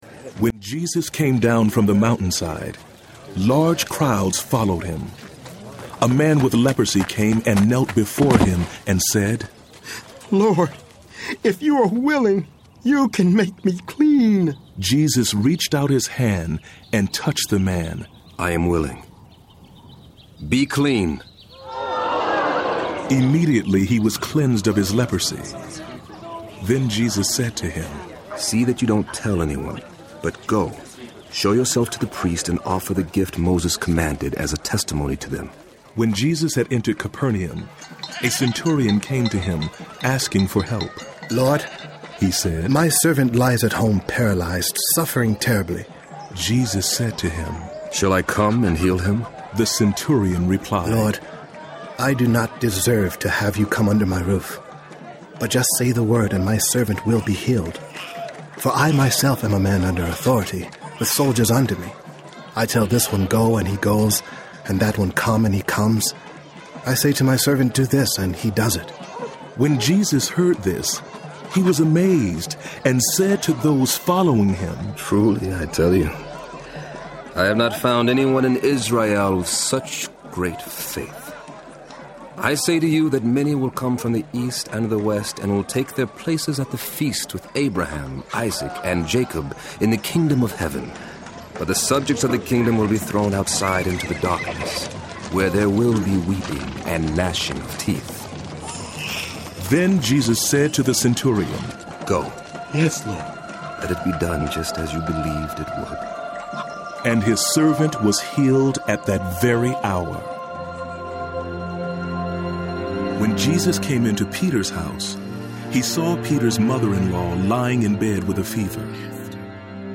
Unique among audio Bible productions, The Bible Experience is a captivating performance of the Bible presented by a stellar ensemble of today’s top-name actors, musicians, clergy, directors, and award-winning producers. This historic production also features a cinematic musical score, unparalleled sound design, and the highest production values.
A star-studded cast has been brought together for a remarkable dramatization of the entire Bible.
20.5 Hrs. – Unabridged